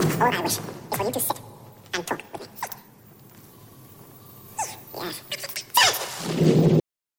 For all experiments, I used a sound clip from one of my favorite movies “Legend” where Tim Curry plays the devil, and Tom Cruise and Mia Sara are the main characters fighting him.
Here’s an even faster / higher pitched version (40% of time):
out_a_fasterhigher.mp3